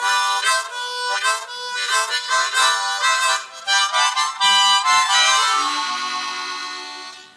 口琴练习剪辑 " 口琴节奏 02
描述：这是M. Honer Marine Band Harmonica的13洞上演奏的节奏音轨的录音。
Tag: 口琴 节奏 重点 G